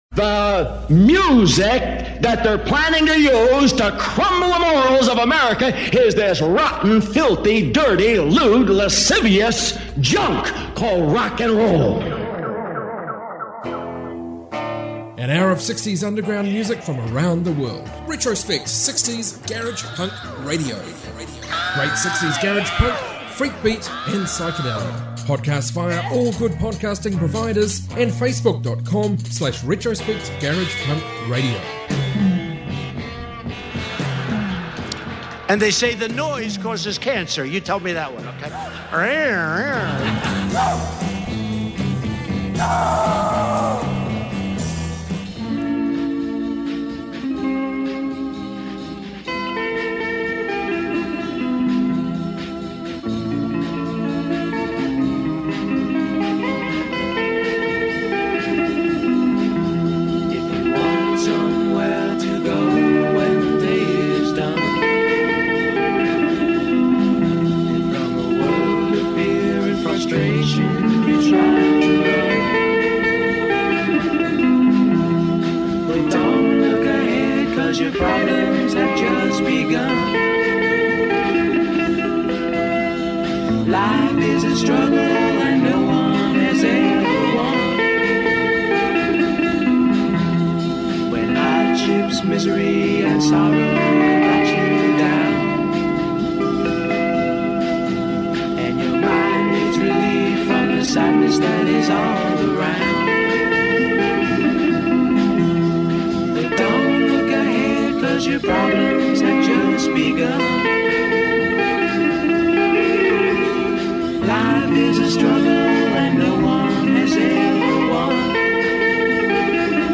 60s garage rock podcast